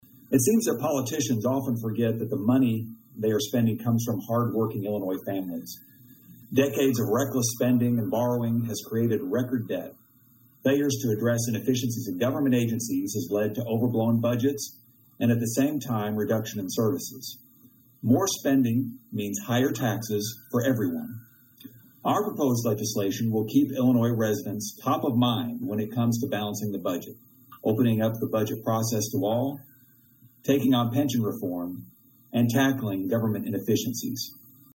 Windhorst said that responsible fiscal leadership needs to be shown in the budget making process.   Attached are comments from the Representative.